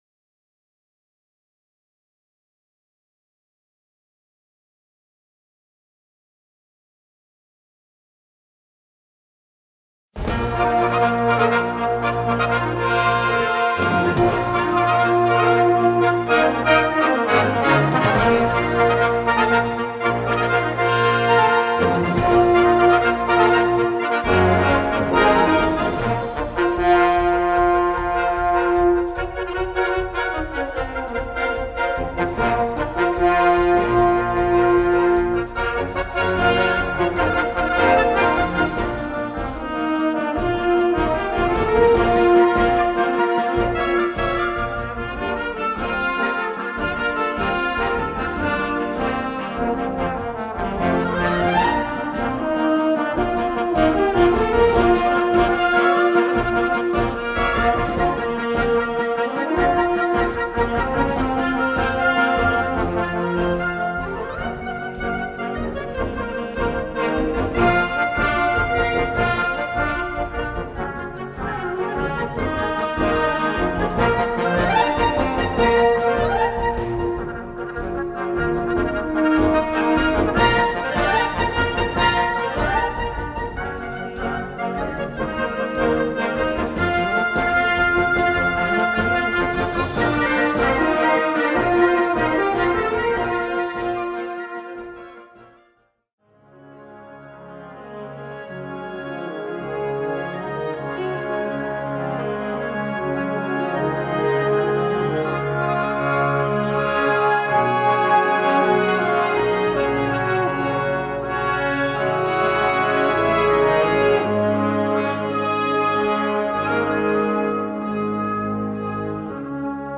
Gattung: Ouvertüre
Besetzung: Blasorchester